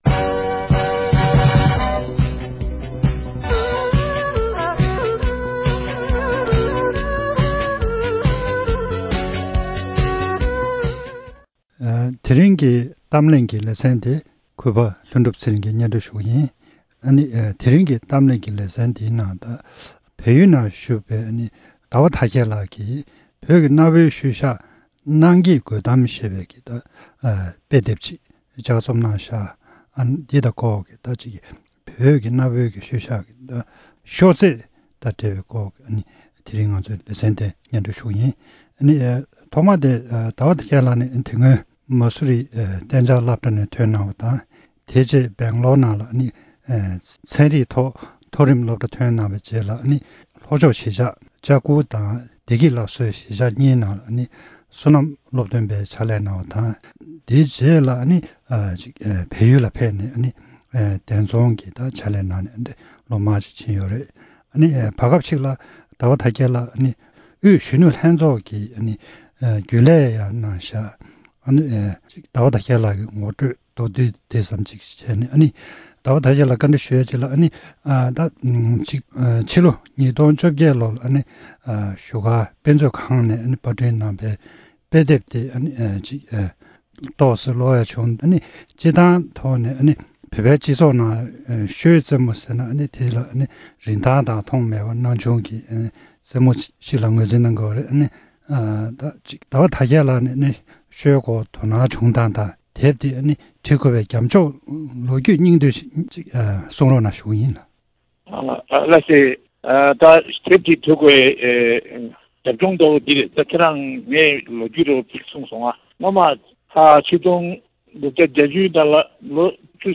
གླེང་མོལ་ཞུས་པ།